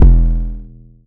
MURDA_808_SIMPLE_E.wav